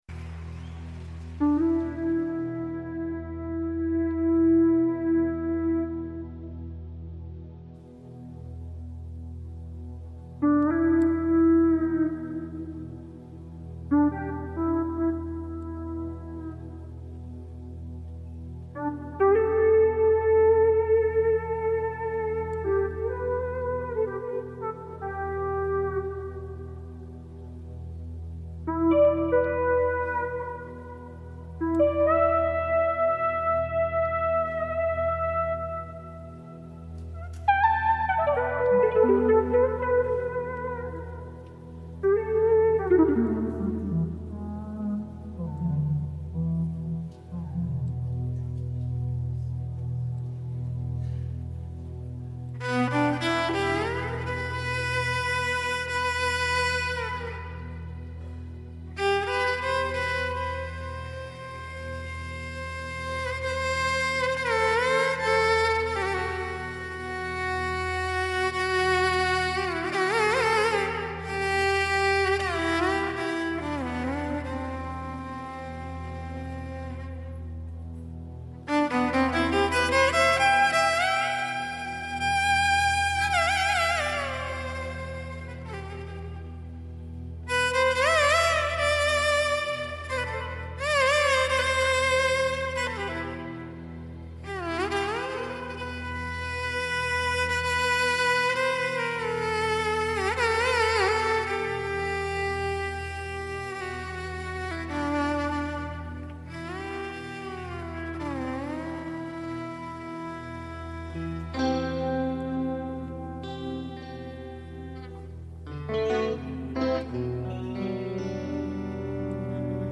Mezzo secolo di commistione tra sonorità jazz e indiane